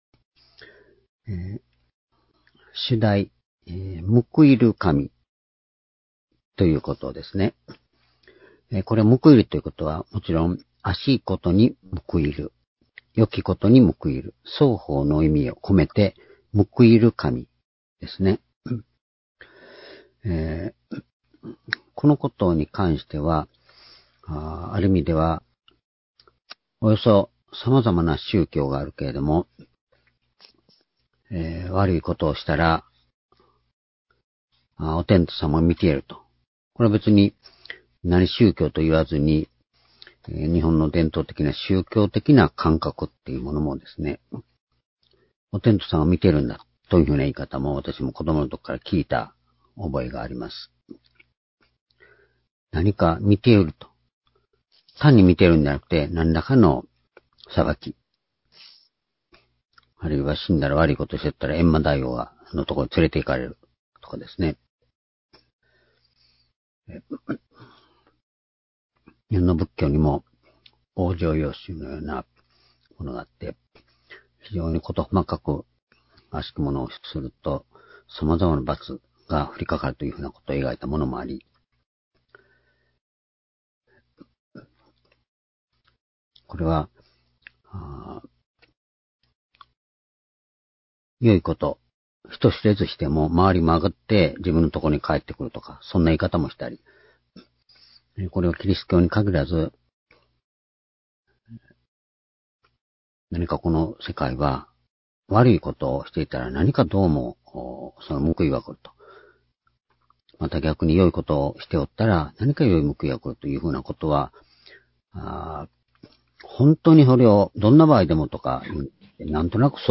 （主日・夕拝）礼拝日時 2021年4月6日（夕拝） 聖書講話箇所 「報いる神」 詩編18編21節～31節 ※視聴できない場合は をクリックしてください。